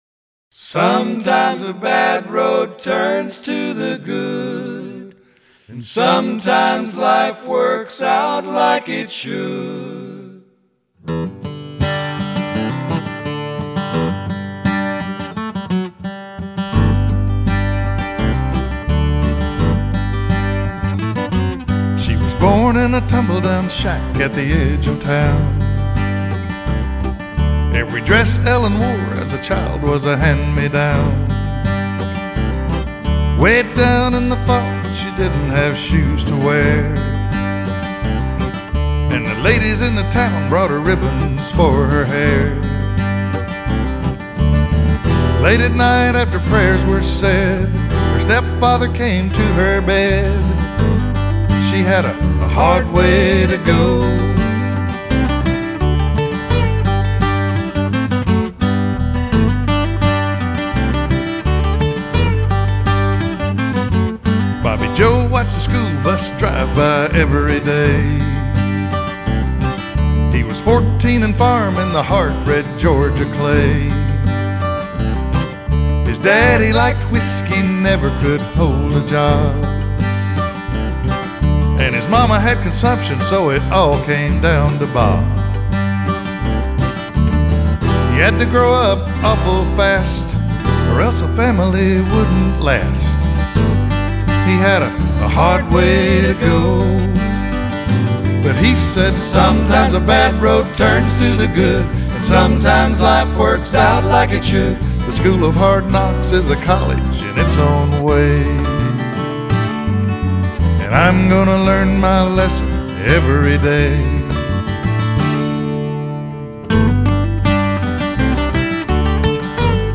studio all originals